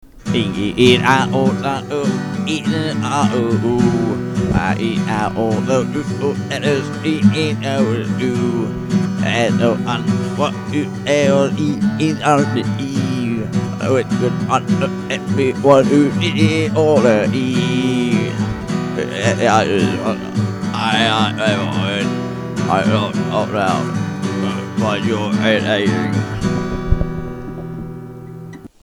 So anyway, all of these are 'demos' unless we can't be bothered to do anything else with them.